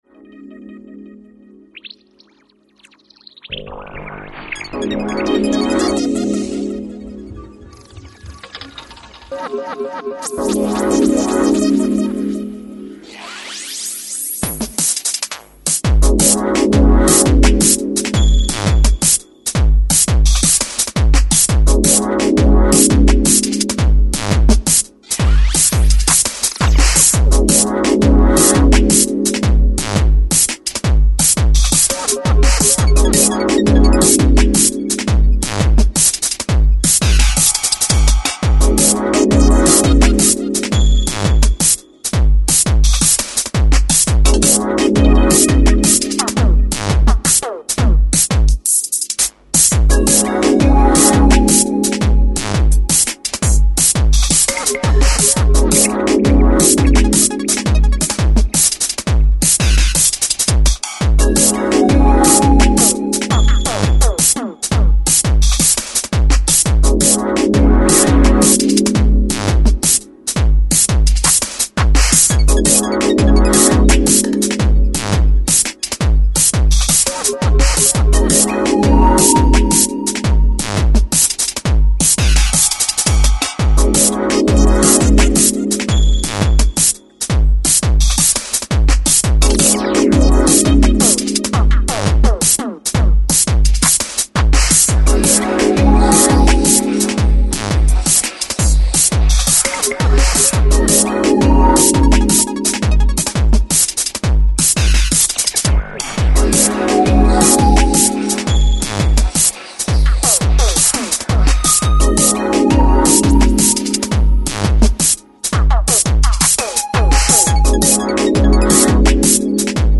futuristic dark sounds
Techstep